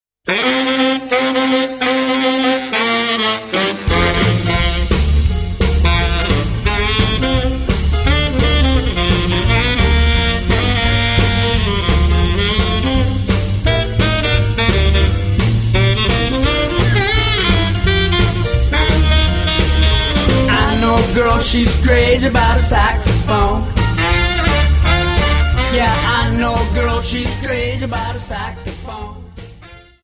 Dance: Jive 43